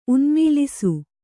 ♪ unmīlisu